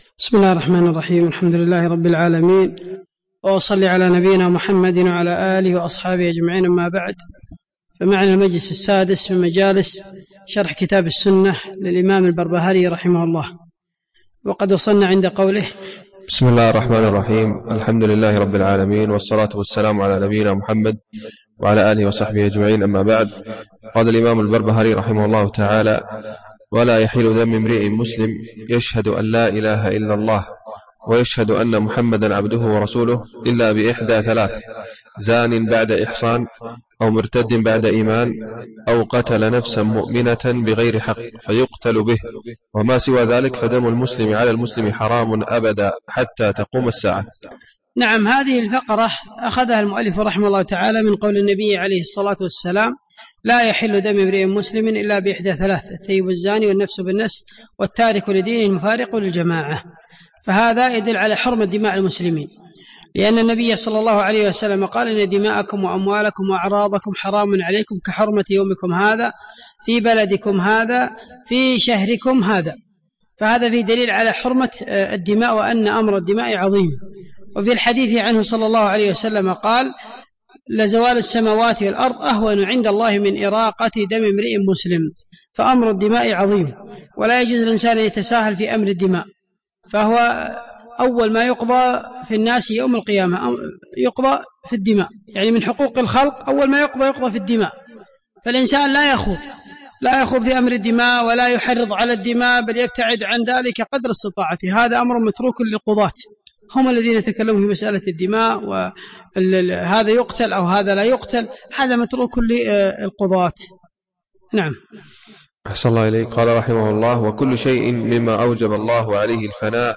الدرس السادس